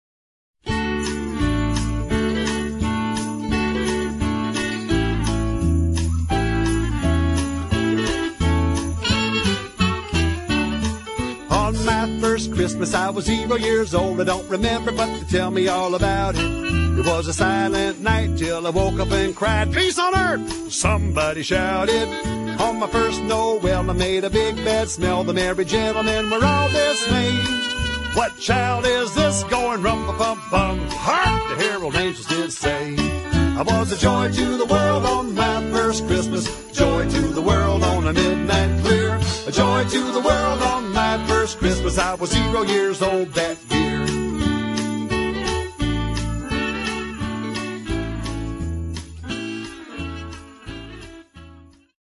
--funny Christmas music